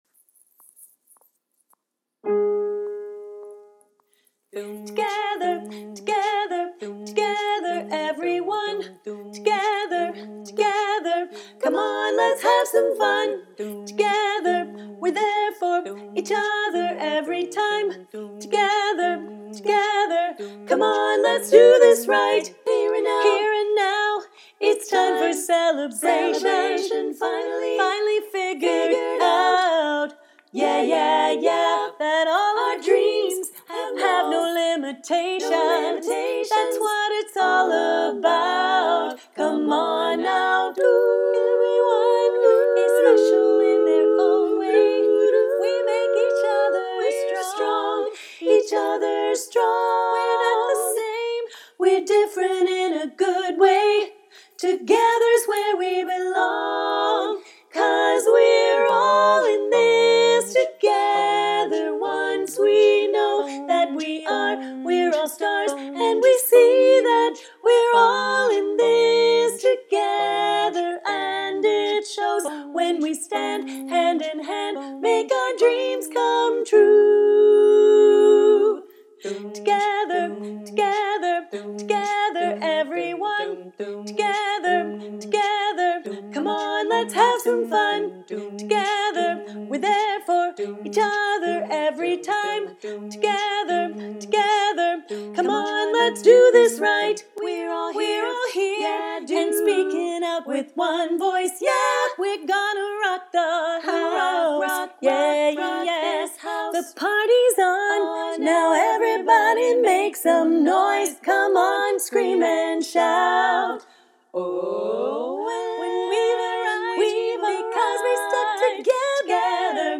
Lead